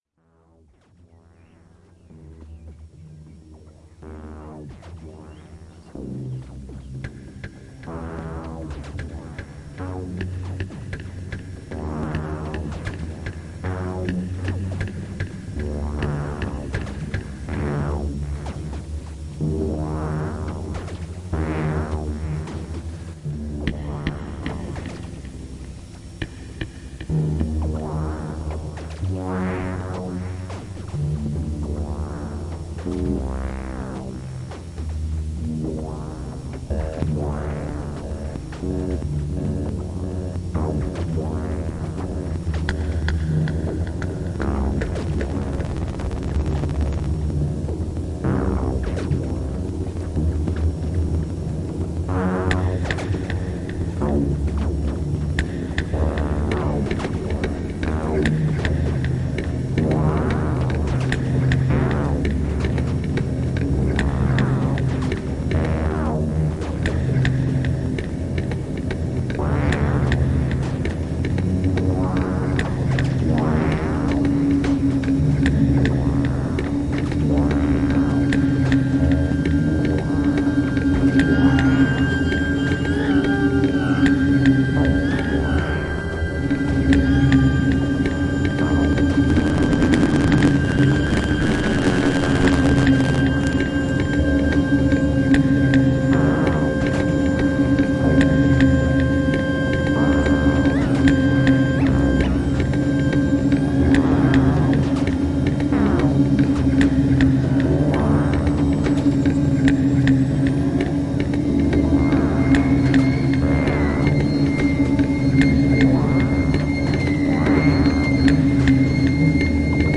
Flute
Synth modular